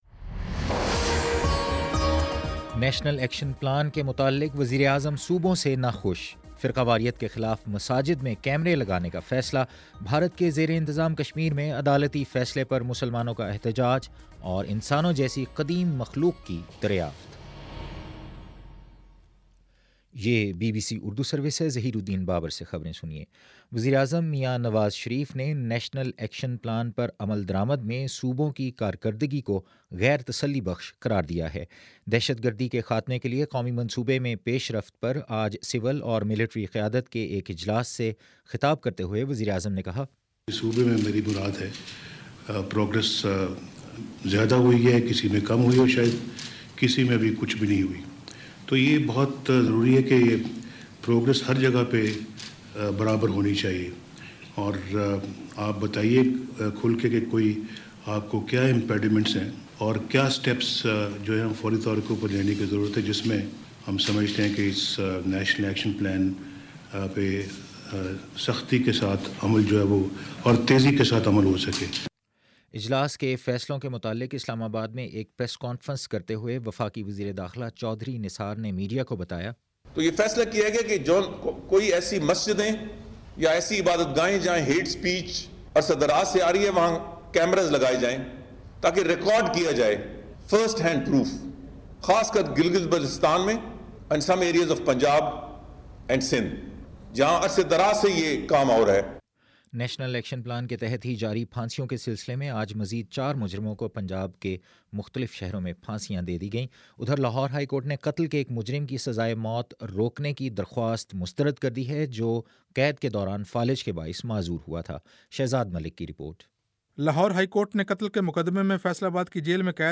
ستمبر10 : شام سات بجے کا نیوز بُلیٹن